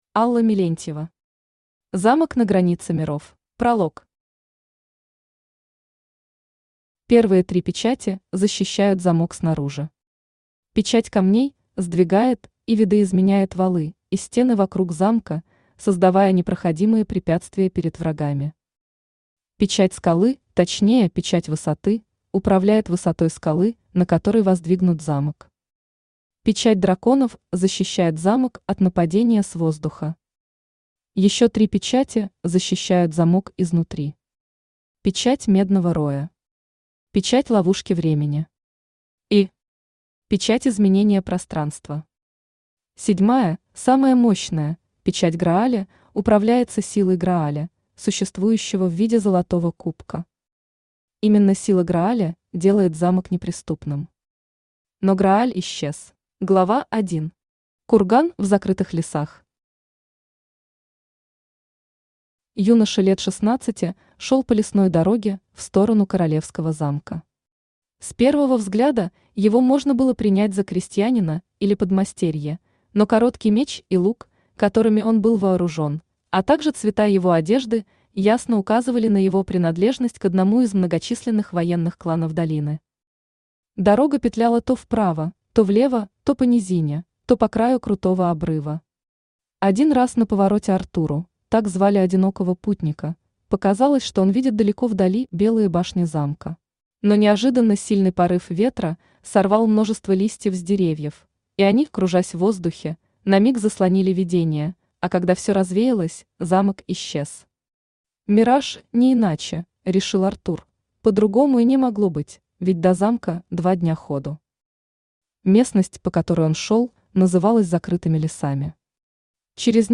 Аудиокнига Замок на границе миров | Библиотека аудиокниг
Aудиокнига Замок на границе миров Автор Алла Мелентьева Читает аудиокнигу Авточтец ЛитРес.